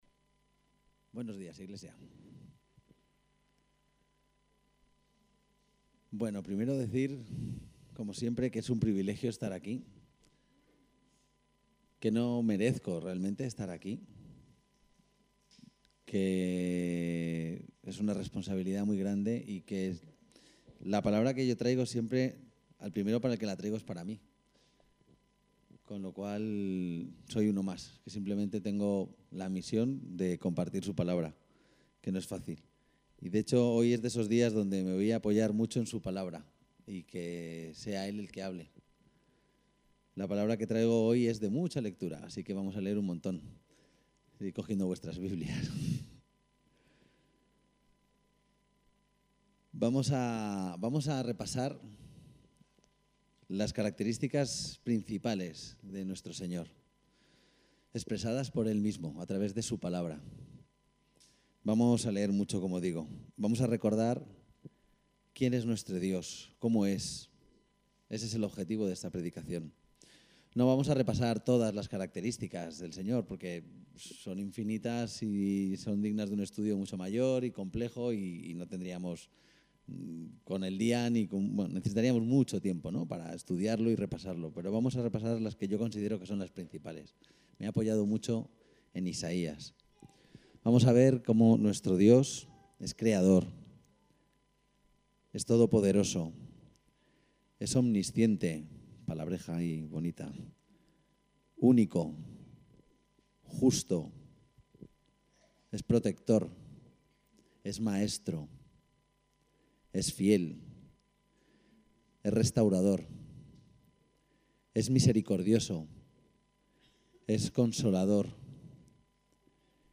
EL texto de la predicación: Las Características de Nuestro Señor_p